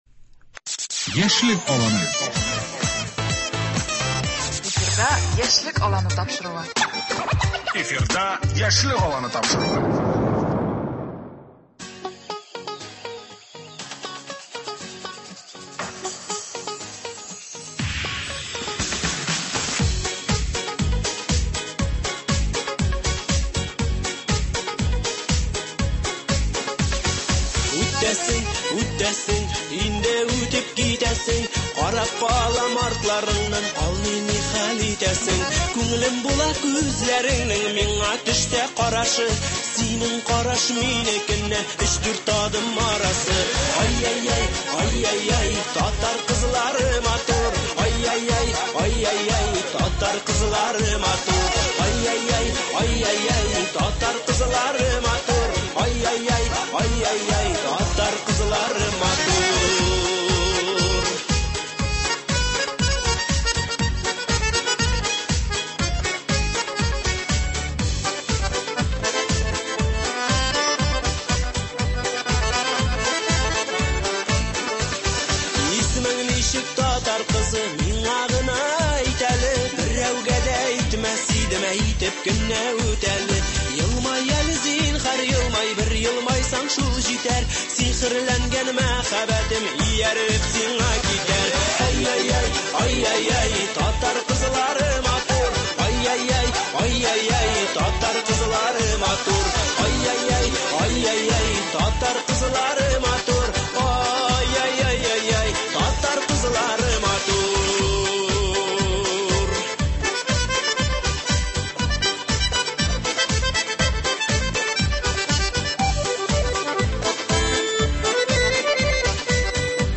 Мәгълүм булганча, 6 нчы март көнне Г.Тукай исемендәге Татар Дәүләт филармониясендә Республикакүләм «Татар кызы» конкуросының финалы узачак. Шул уңайдан бүген студиябездә яшь, чибәр, уңган-булган татар кызларын сәламлибез.